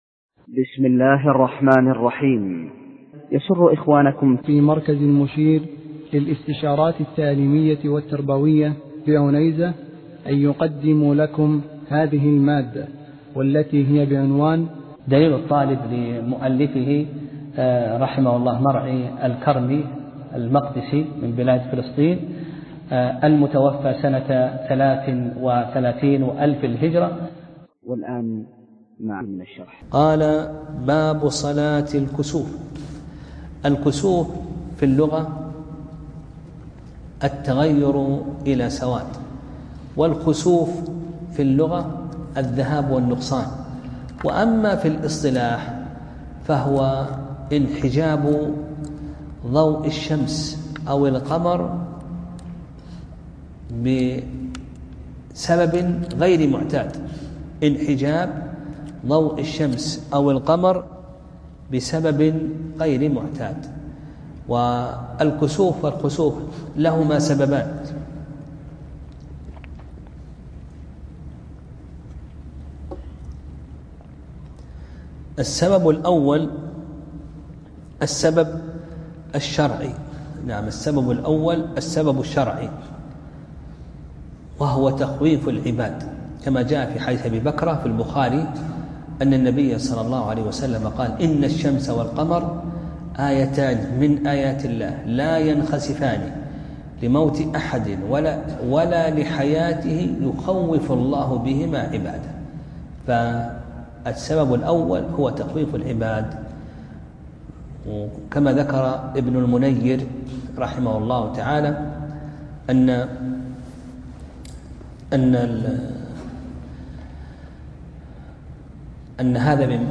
درس (23) : باب صلاة الكسوف